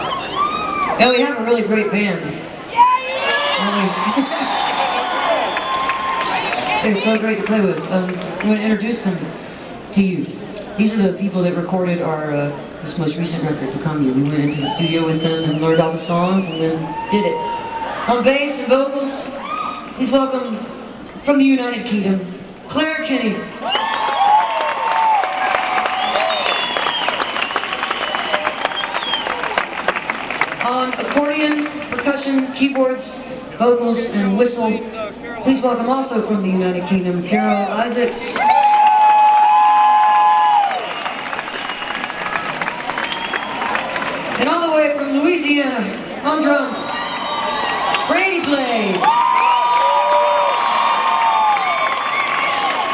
05. band introductions (0:52)